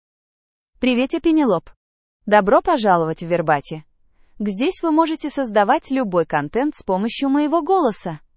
Penelope — Female Russian AI voice
Penelope is a female AI voice for Russian (Russia).
Voice sample
Listen to Penelope's female Russian voice.
Female
Penelope delivers clear pronunciation with authentic Russia Russian intonation, making your content sound professionally produced.